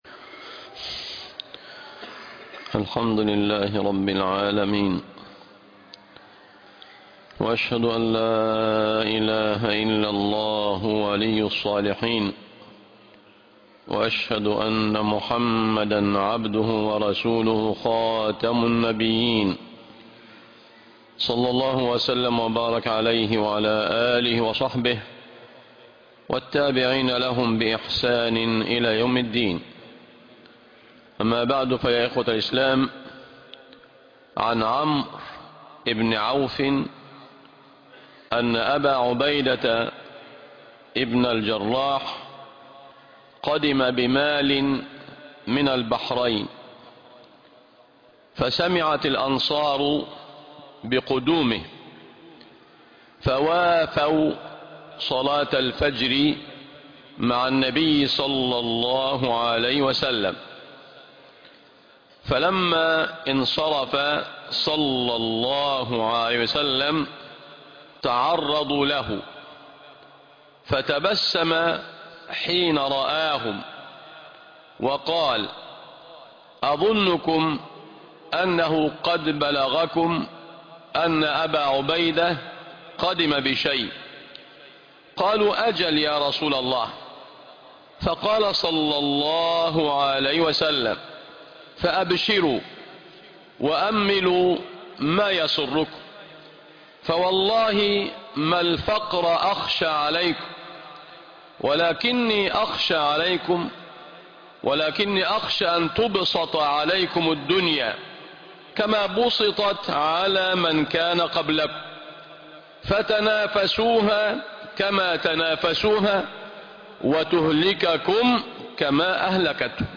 صناعة الأمل الجمعة ٣ رجب _١٤٤٦هـ_ ٣ يناير ٢٠٢٥م مجمع نور التوحيد بالشين